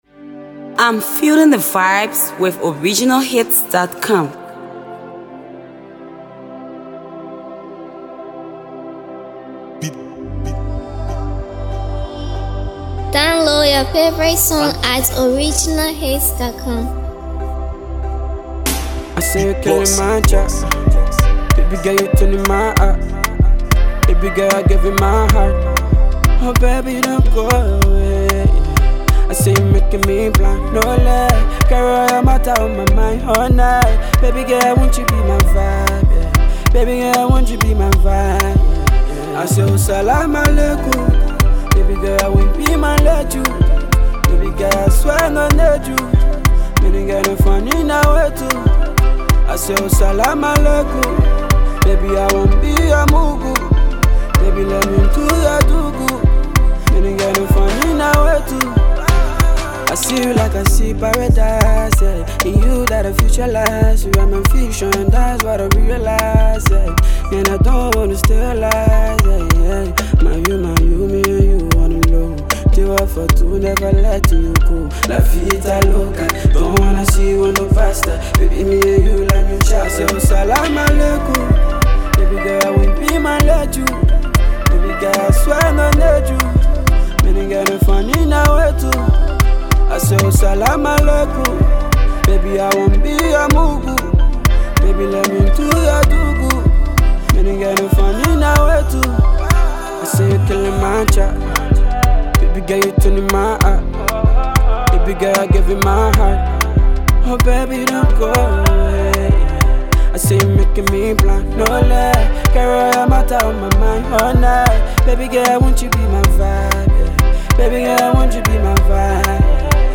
full of positive vibes and a killer rhythm.
which combines Afropop, RnB, and Afrobeat.